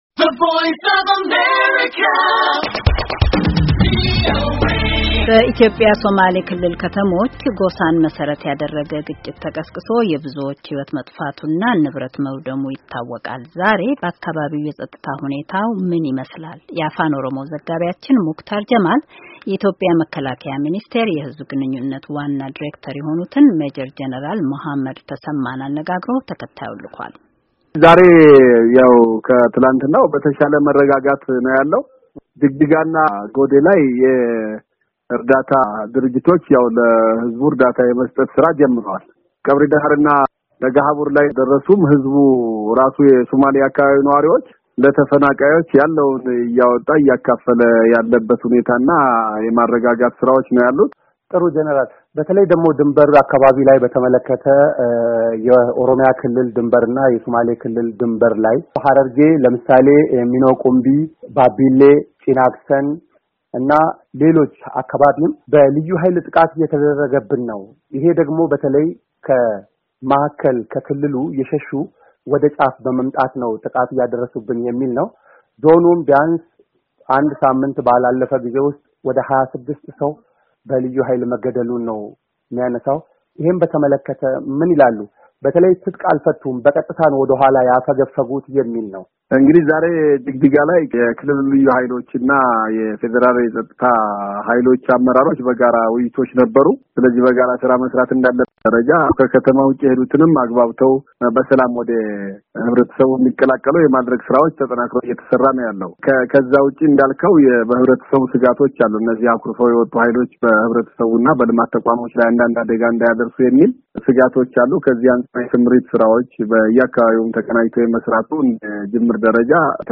የኢትዮጵያ መከላከያ ሚኒስትር የሕዝብ ግንኙነት ዋና ዳይሬክተር መጀር ጄኔራል መሐመድ ተሰማን አነጋግሯል፡፡